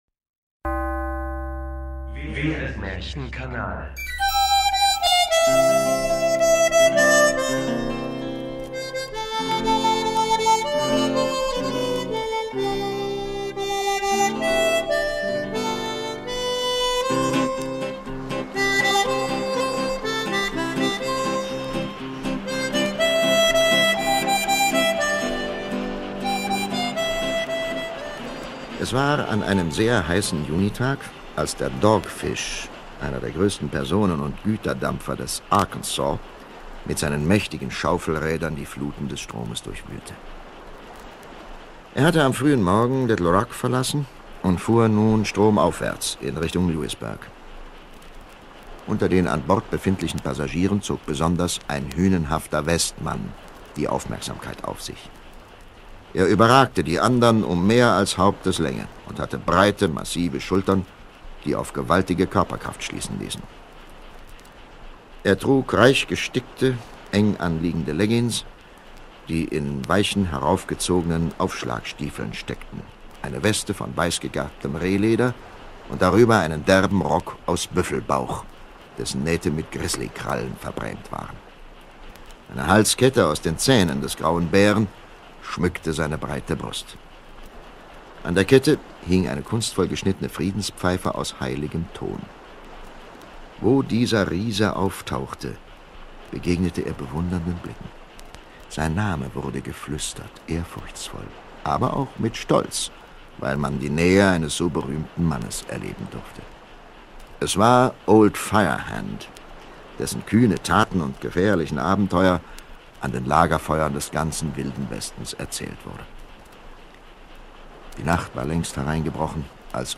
Karl May - Der Schatz im Silbersee - Märchen Hörspiel - PEG (128kbit_AAC).m4a